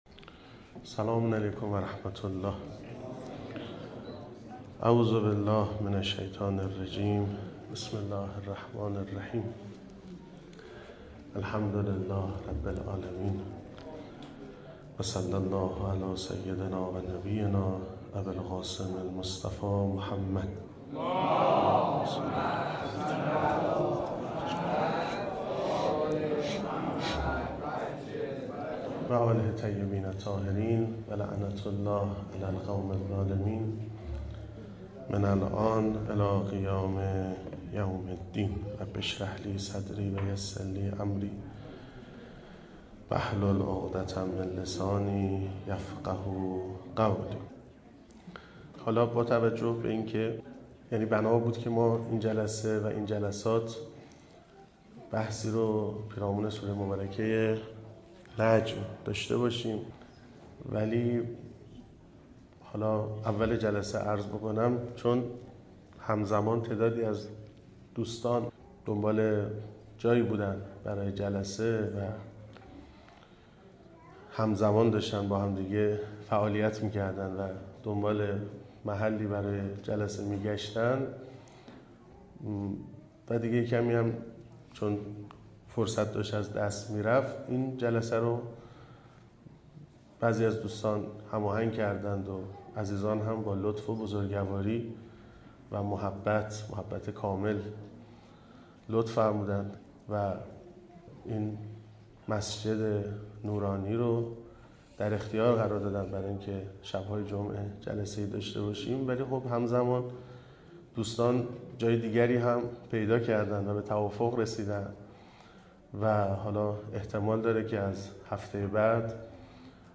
در این سخنرانی اون قسمت هانی بن عروه خیلی خوب بود و چسبید